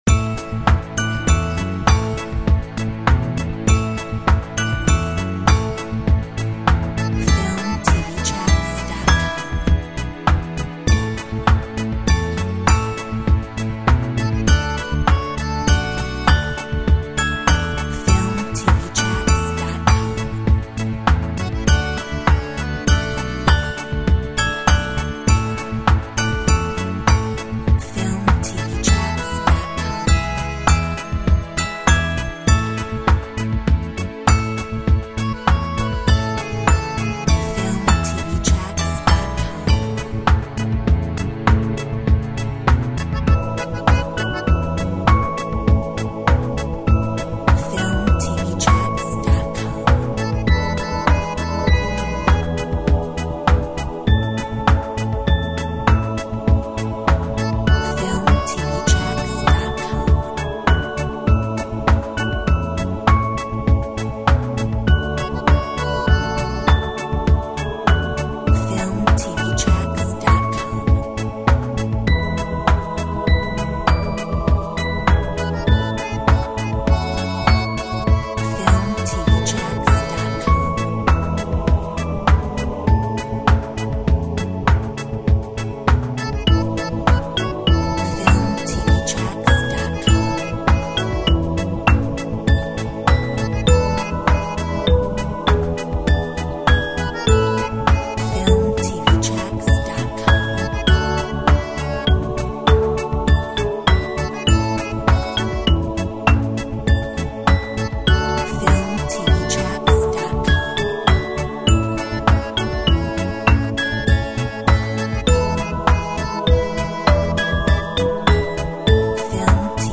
Corporate , POP